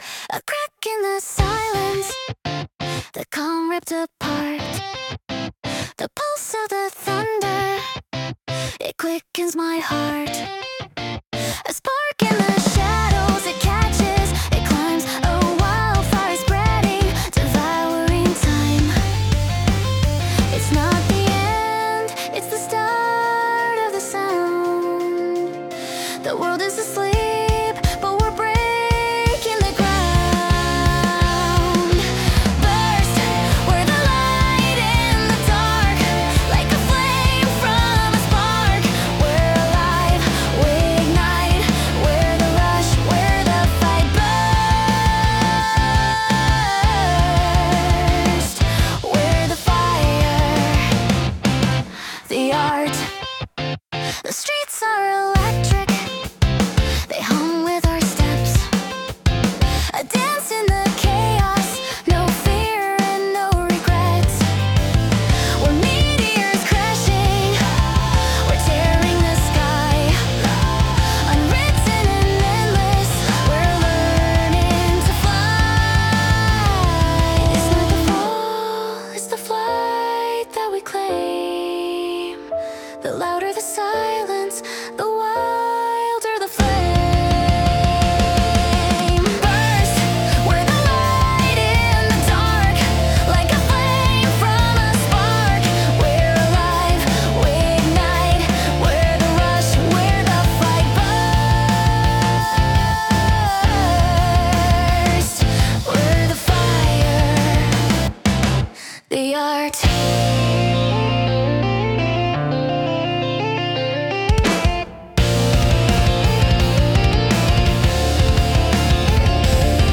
アニメ音楽は、日本のアニメ主題歌をイメージしたジャンルで、ポップでキャッチーなメロディとドラマチックな展開が特徴です。